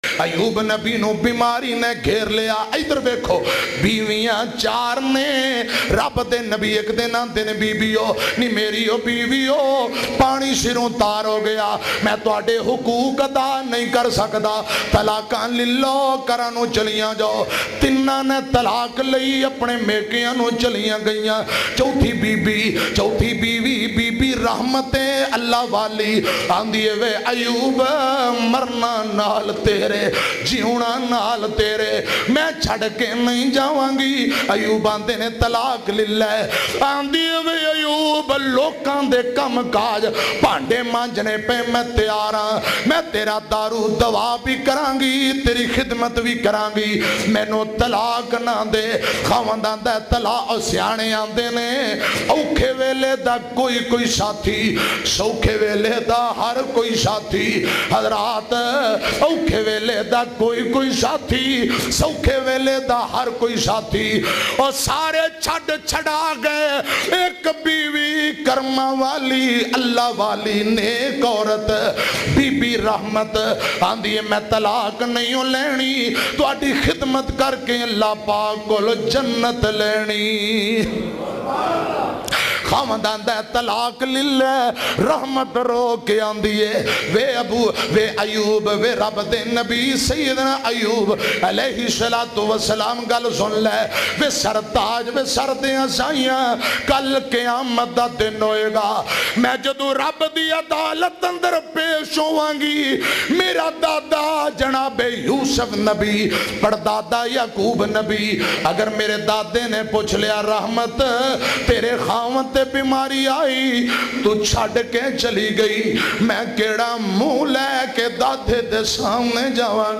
Biwi Aur Khawand Ka Rula Denay Wala Waqia bayan mp3